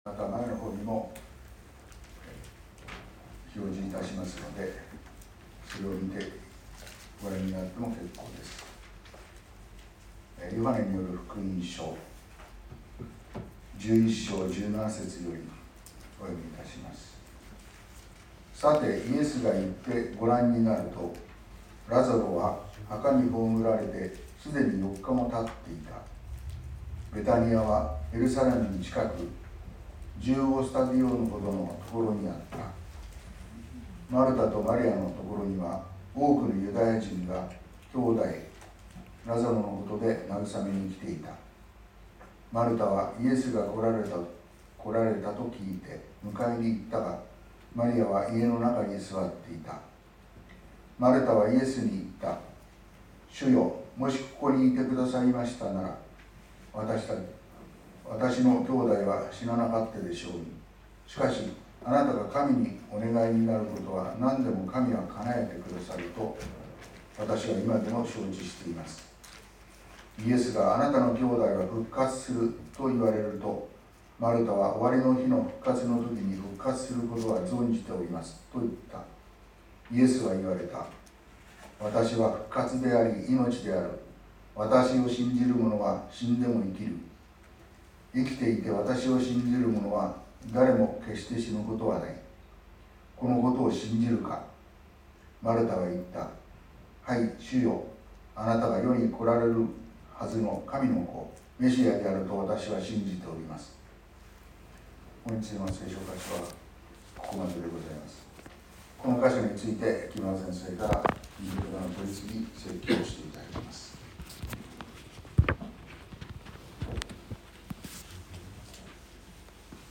Youtubeで直接視聴する 音声ファイル 礼拝説教を録音した音声ファイルを公開しています。